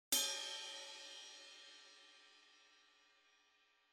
Striking the bell turns the “ping” to a “clang”, reducing the wash and is commonly used for accenting and quarter note ride patterns in hard rock.
Ride Cymbal: Bell
ride_bell.mp3